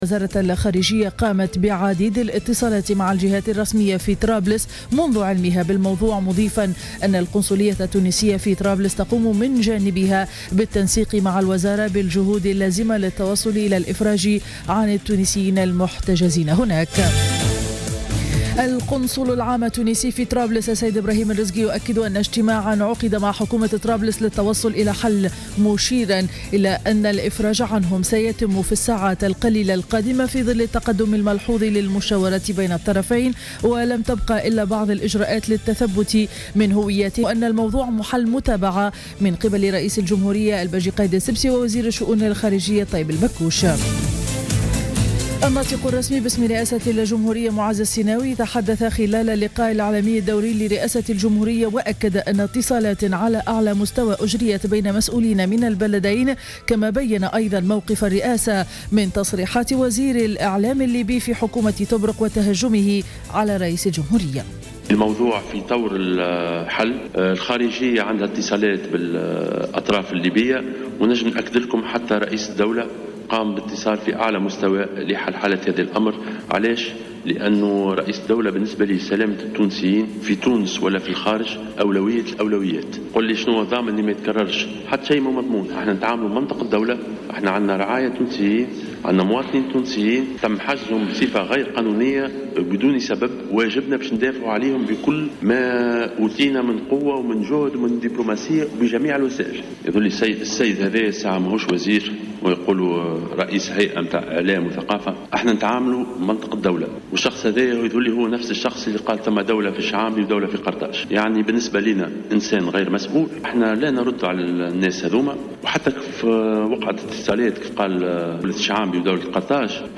نشرة أخبار السابعة صباحا ليوم الثلاثاء 19 ماي 2015